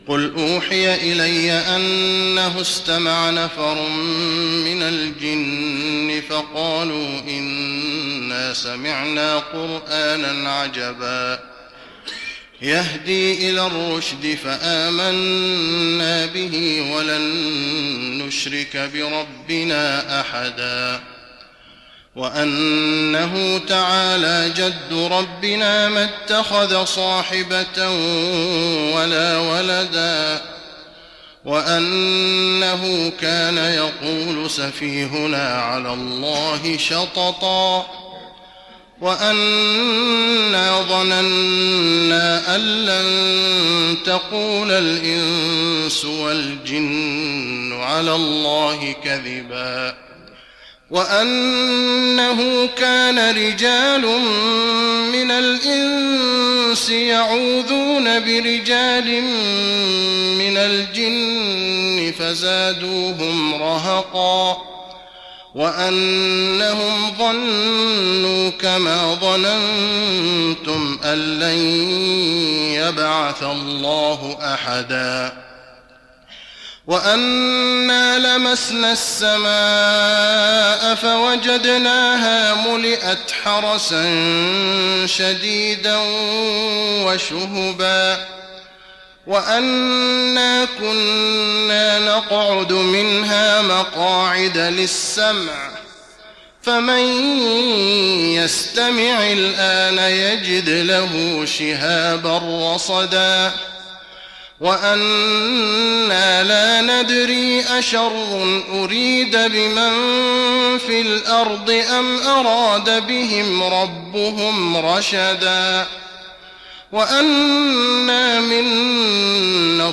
تراويح رمضان 1415هـ من سورة الجن الى سورة المرسلات Taraweeh Ramadan 1415H from Surah Al-Jinn to Surah Al-Mursalaat > تراويح الحرم النبوي عام 1415 🕌 > التراويح - تلاوات الحرمين